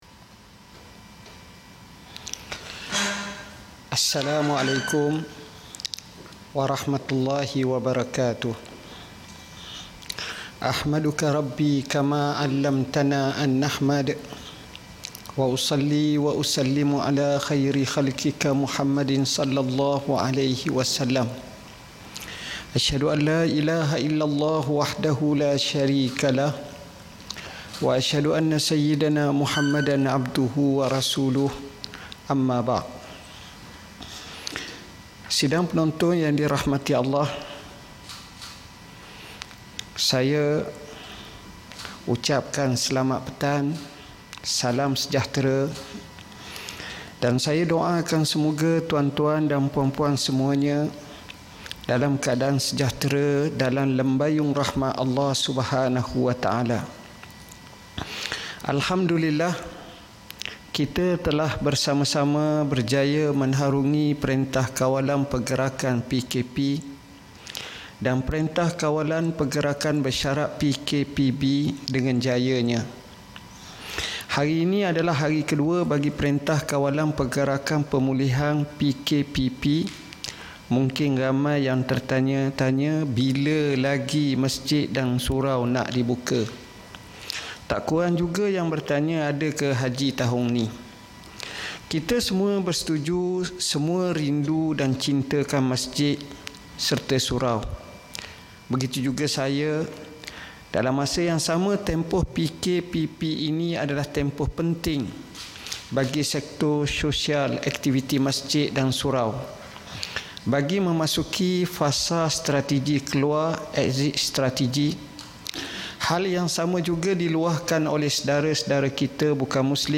Sidang media Menteri di Jabatan Perdana Menteri Hal Ehwal Agama
Ikuti sidang media Menteri di Jabatan Perdana Menteri Hal Ehwal Agama oleh YB Senator Datuk Dr. Zulkifli Mohamad Al-Bakri.